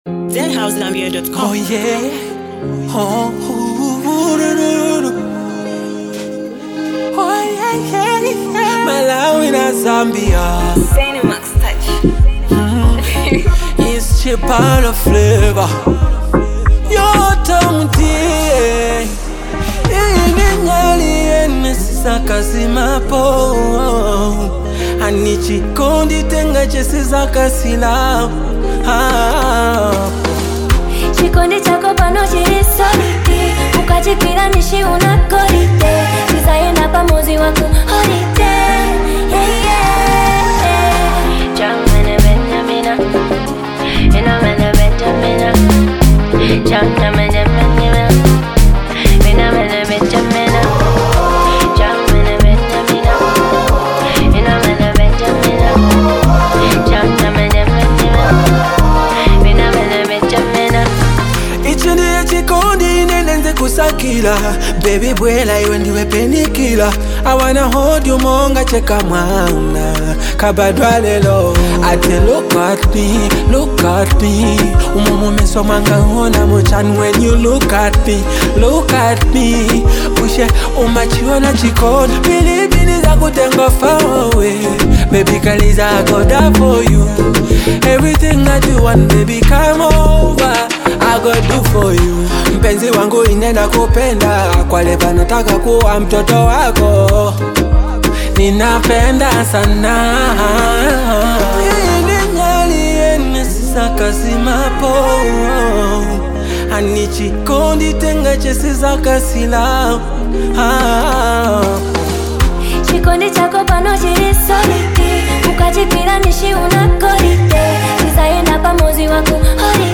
deeply emotional record